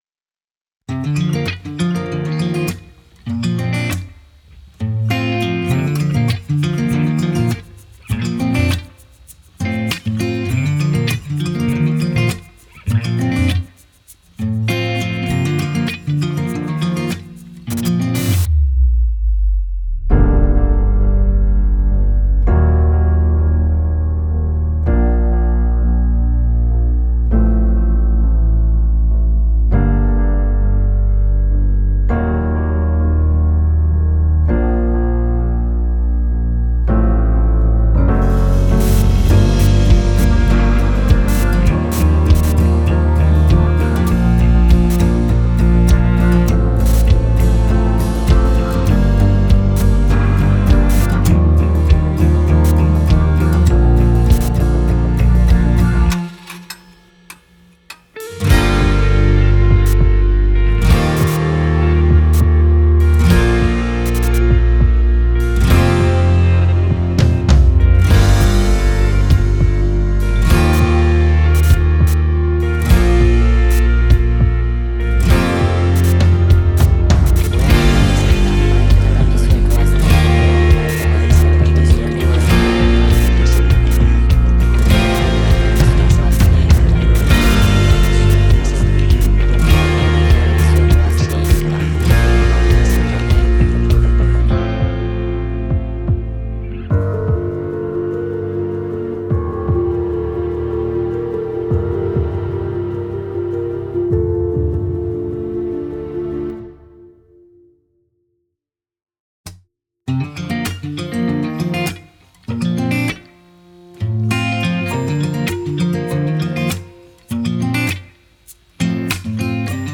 オリジナルKey：「Bb